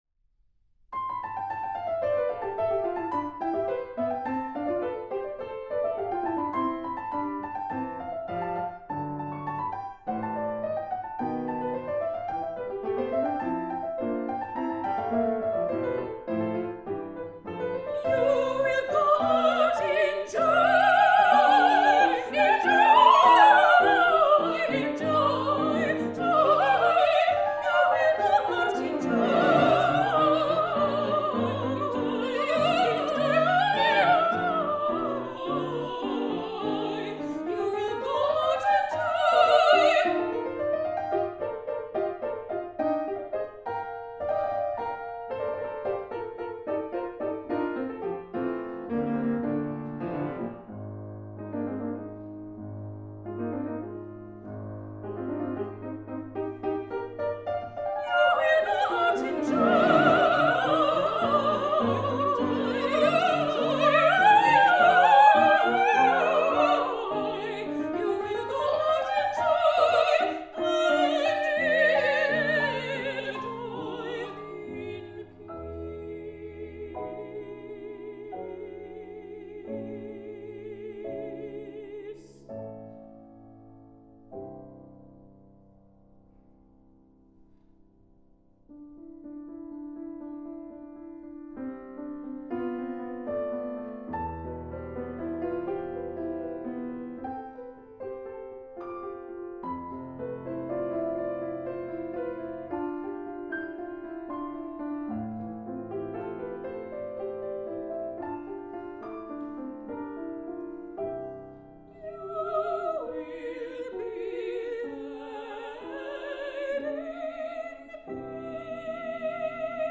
soprano
mezzo-soprano
piano.
Duet for soprano & mezzo-soprano, piano